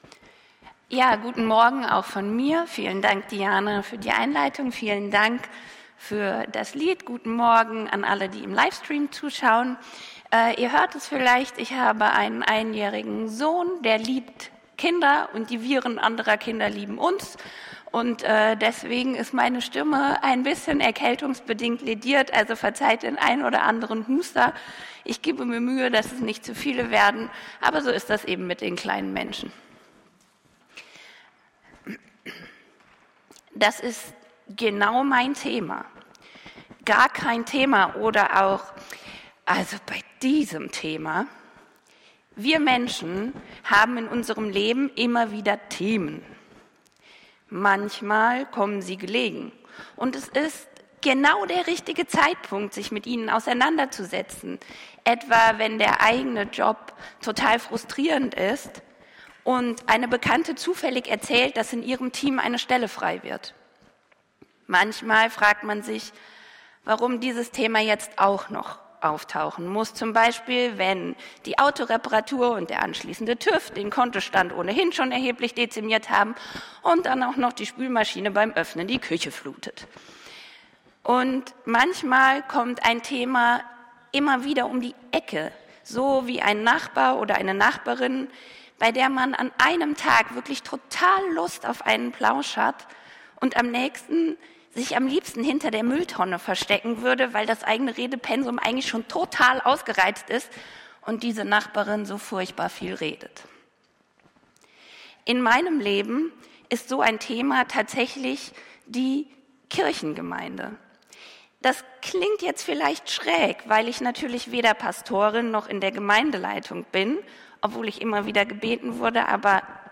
Predigt vom 10.09.2023